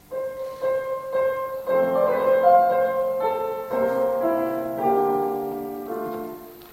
我现在给你弹两种，一种是都对，但不是dolce：
你现在弹两种，第一种是都对但有点木的那种，第二种是带着爱去弹。